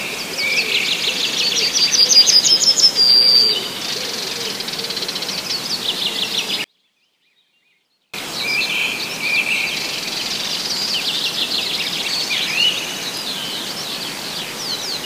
ястребиная славка, Curruca nisoria
Svītrainais ķauķis iesaucas divas reizes trīs minūšu ieraksta laikā. Atstarpes starp saucieniem saīsinātas.